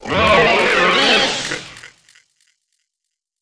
Techno / Voice